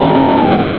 Cri de Barbicha dans Pokémon Rubis et Saphir.
Cri_0340_RS.ogg